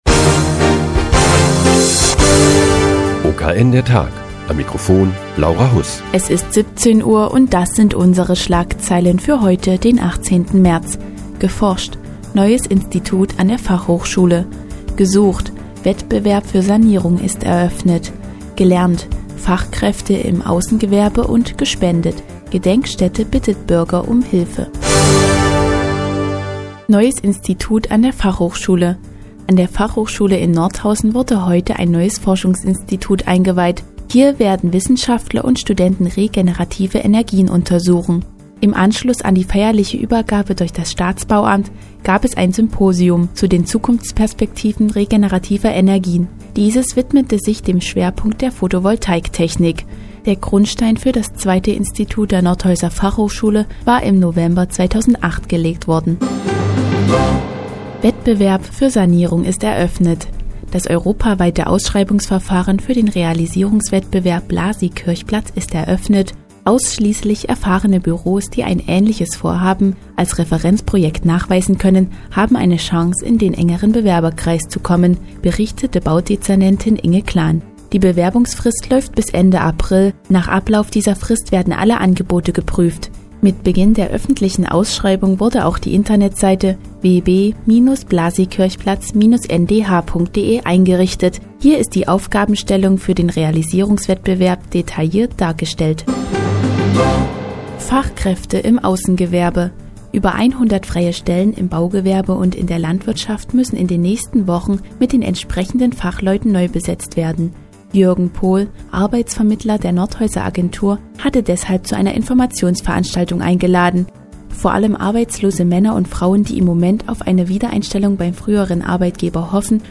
Die tägliche Nachrichtensendung des OKN ist nun auch in der nnz zu hören. Heute geht es um das neue Forschungsinstitut an der Fachhochschule Nordhausen und die Ausschreibung für den Realisierungswettbewerb "Blasiikirchplatz".